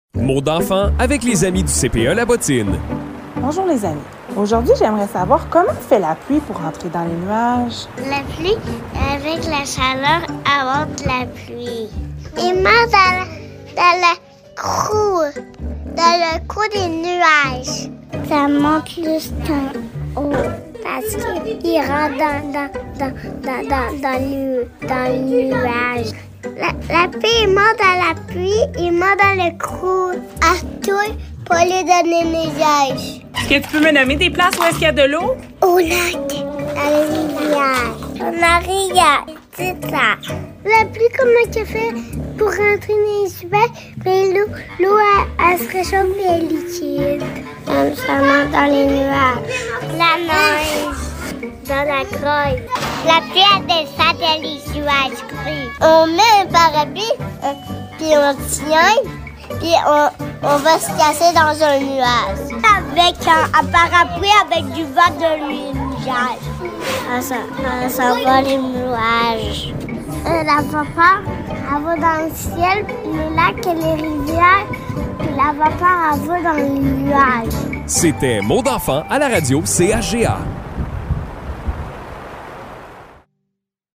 Les enfants du CPE La Bottine se questionnent sur la façon dont la pluie se rend dans les nuages.